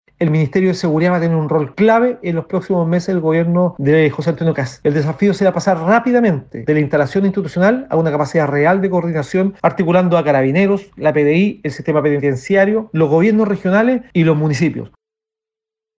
Por su parte, el diputado Hugo Rey (RN) afirmó que el rol del Ministerio de Seguridad será clave durante los primeros meses del nuevo periodo presidencial.